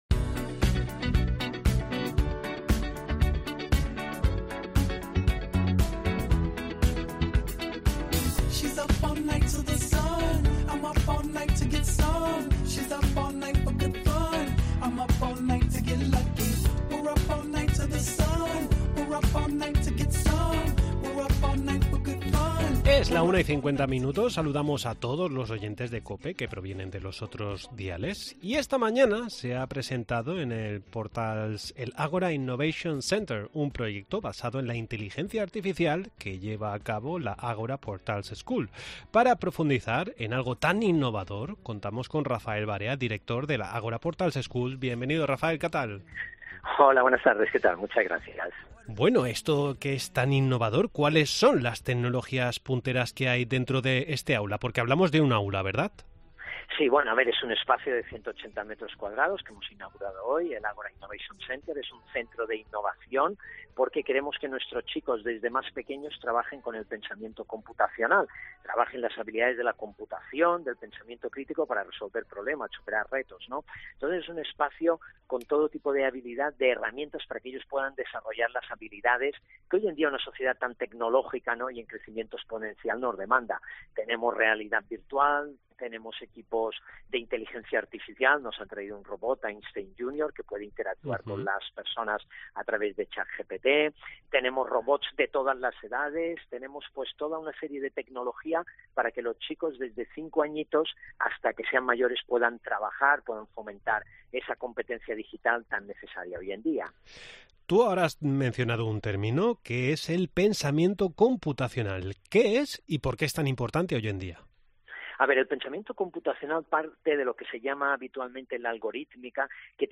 Hoy se ha presentado en Portals el Ágora innovation Centre, un proyecto basado en la inteligencia artificial que lleva a cabo la Agora Portals School. Hablamos con